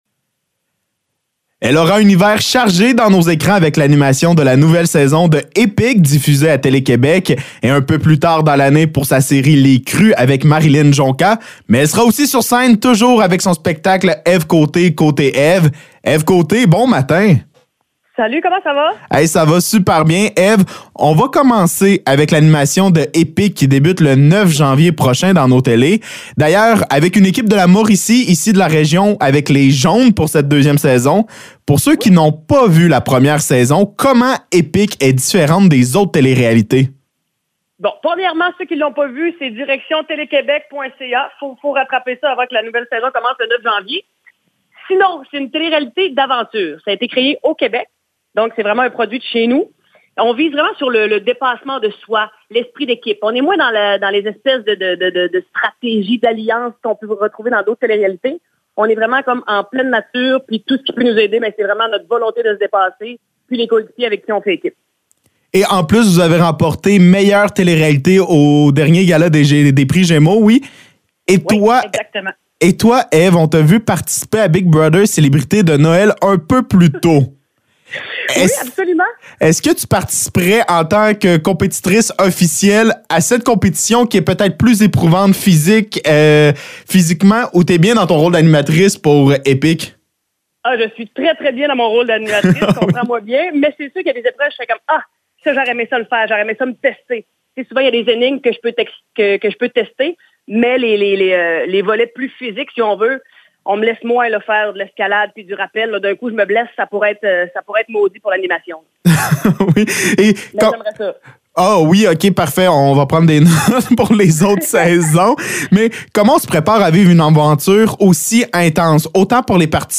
Entrevue avec Ève Côté